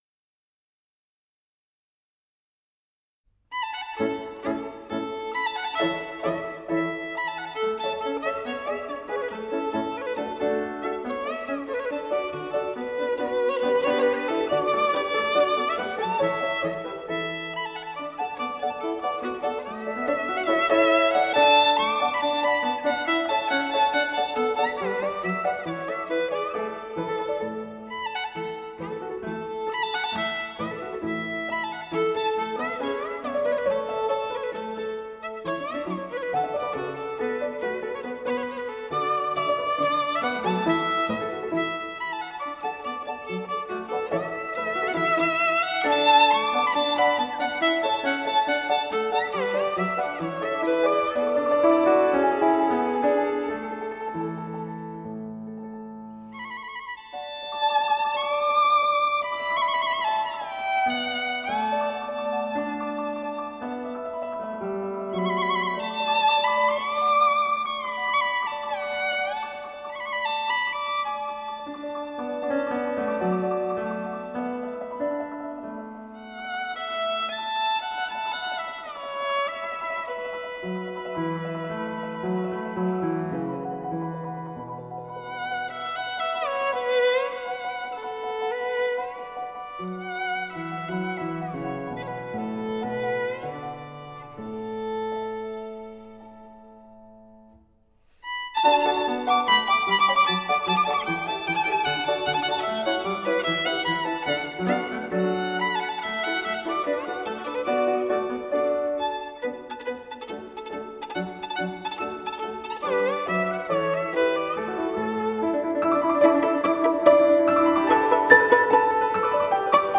唱片的录音效果也非常优秀，音色干净利落。